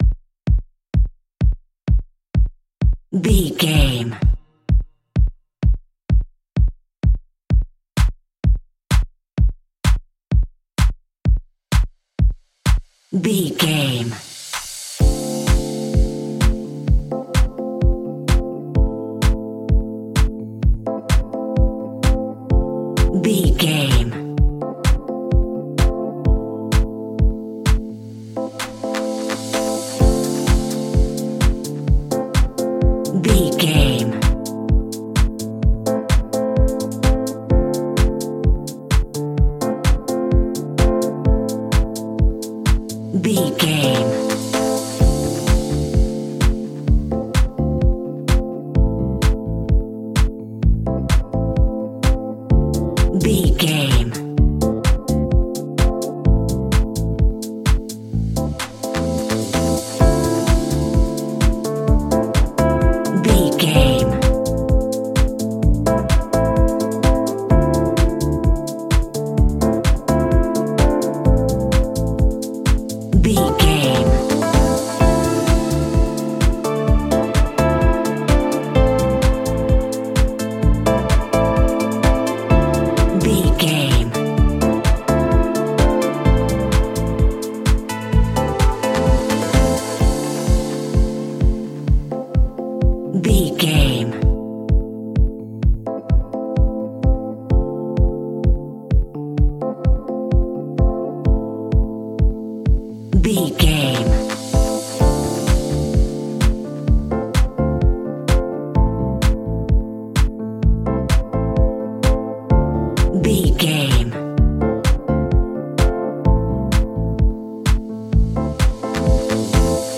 Aeolian/Minor
groovy
uplifting
energetic
drums
drum machine
synthesiser
electric piano
bass guitar
funky house
deep house
nu disco
upbeat
funky guitar
wah clavinet